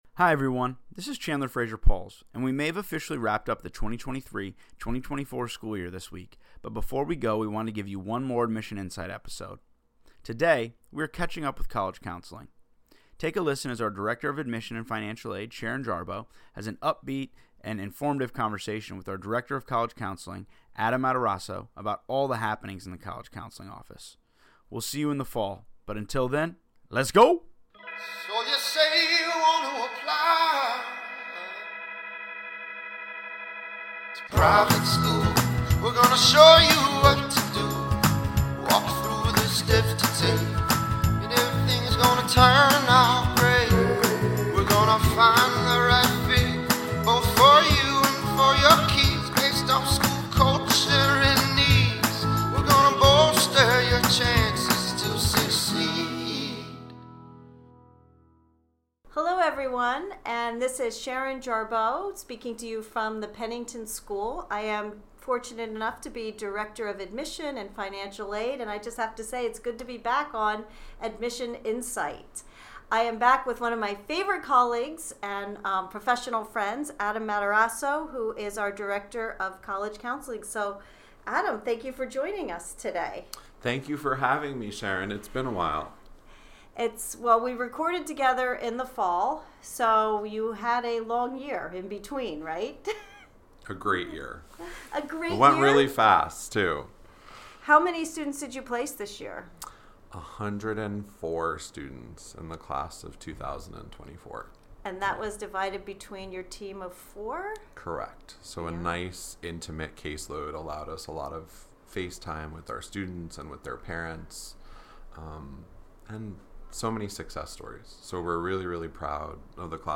has an upbeat and informative conversation with our Director of College Counseling